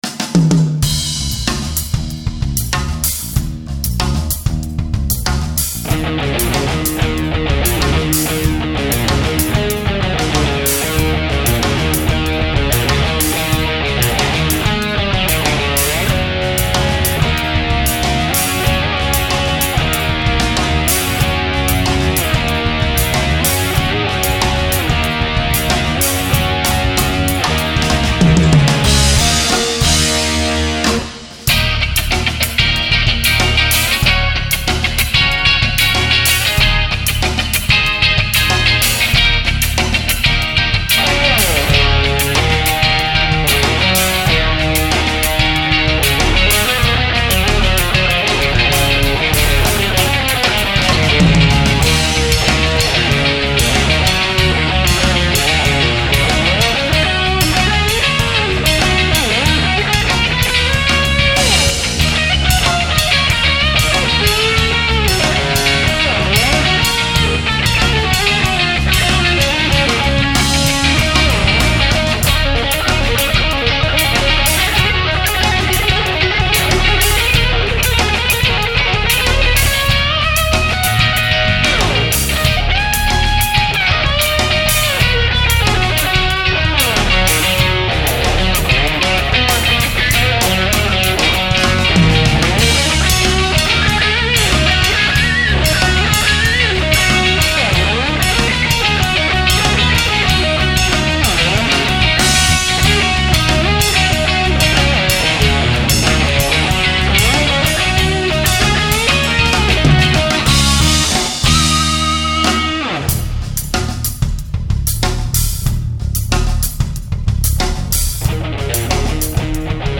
Guiter
Bass
Drums